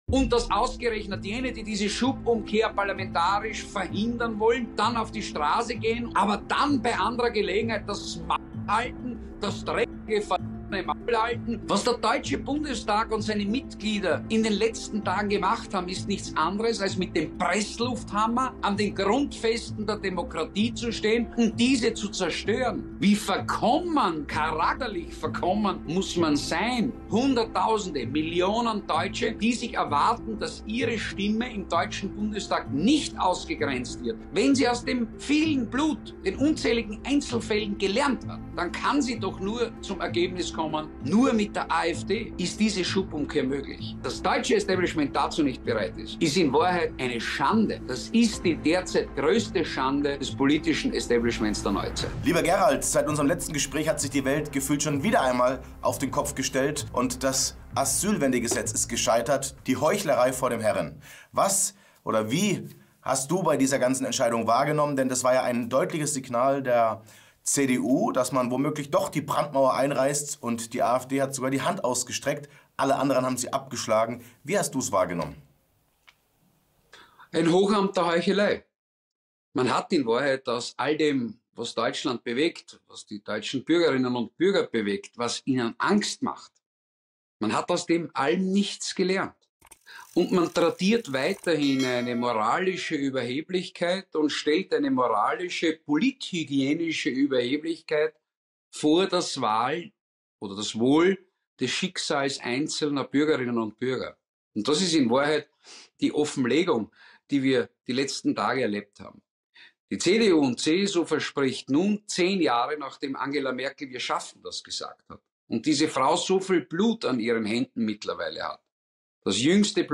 POLITIK-HAMMER_Das_Migrations-Dilemma_der_Union_Gerald_Grosz_im_Interview.mp3